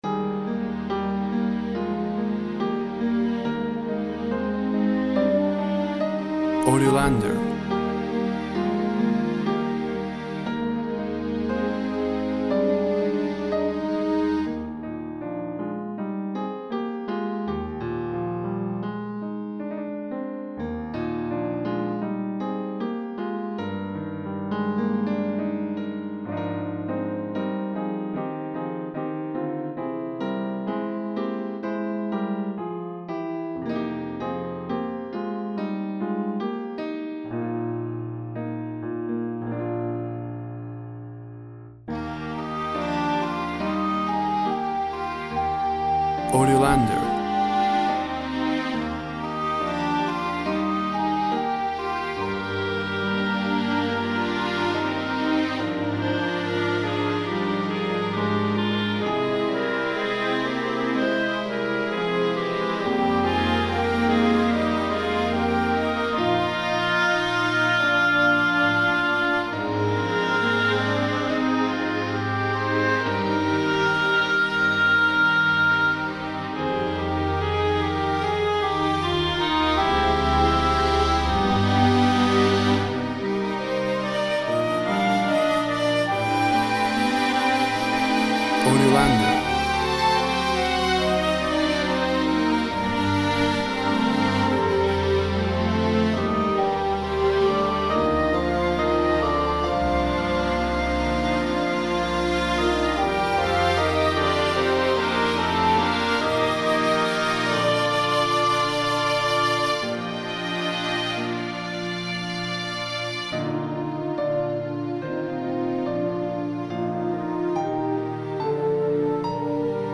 Piano And Orchesta,very emotive, music for film.
Tempo (BPM) 85